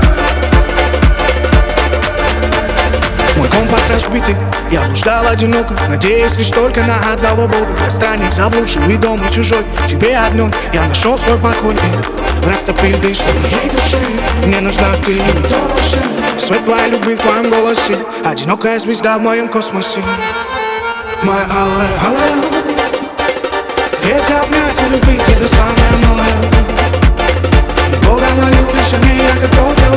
Pop Alternative Indie Pop